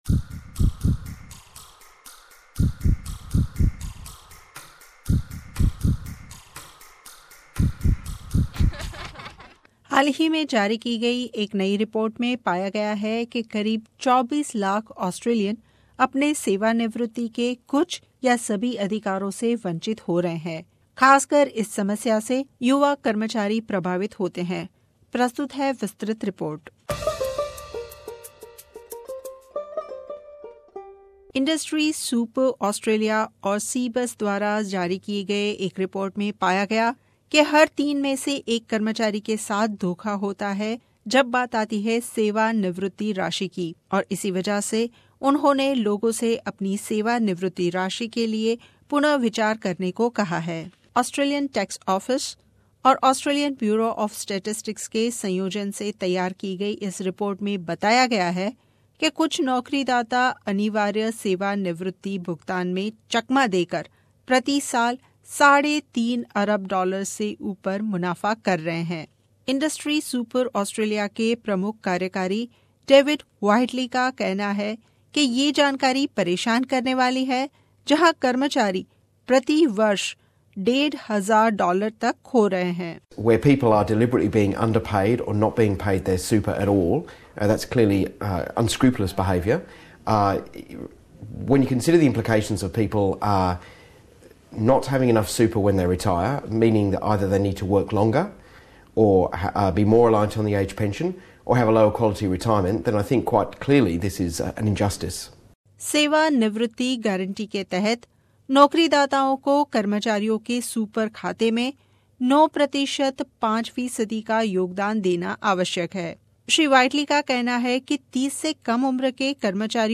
सेवानिवृत्ति राशि बहोत ही महत्वपूर्ण है लेकिन हालही में जारी एक रिपोर्ट में पाया गया के ज्यादातर कर्मचारियोंको ये मालूम ही नहीं के उनके नोकरीदाता उनके सुपर एकाउंट में सही राशि जमा क्र रहे है के नहीं। प्रस्तुत है इस विषय पर रिपोर्ट